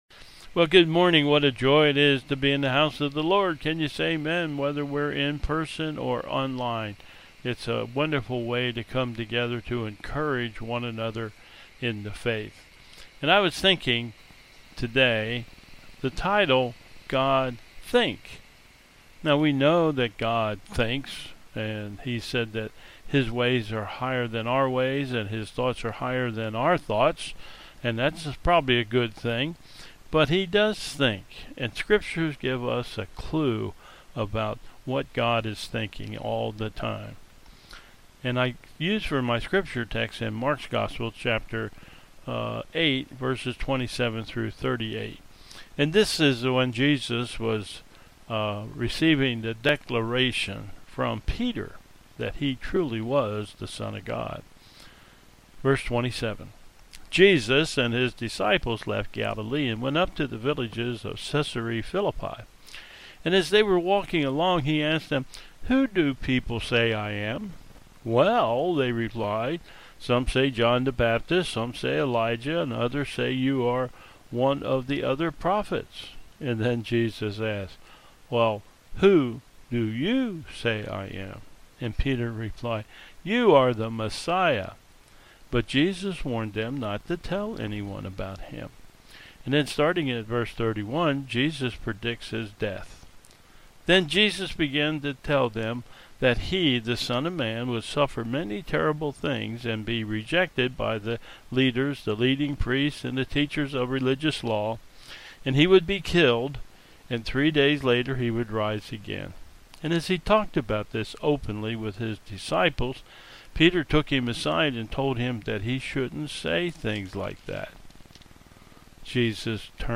"Youth" Message : " God Think!